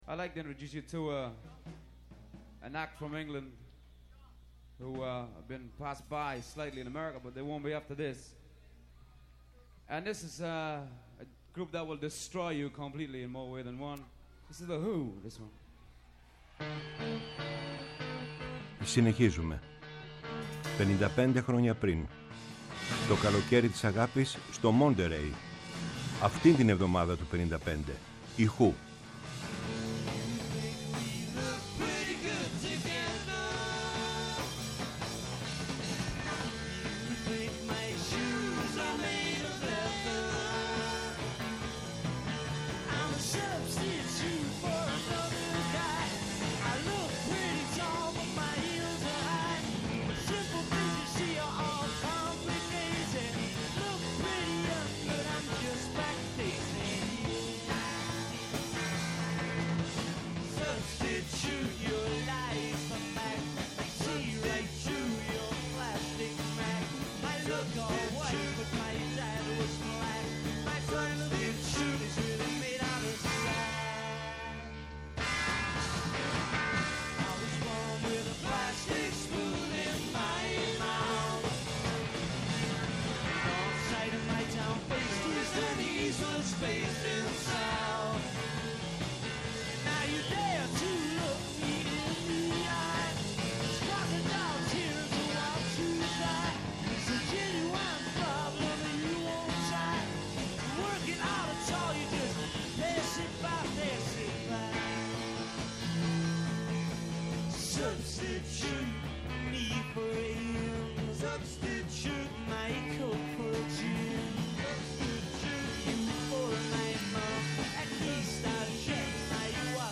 Η μακροβιότερη εκπομπή στο Ελληνικό Ραδιόφωνο!
ΜΟΥΣΙΚΗ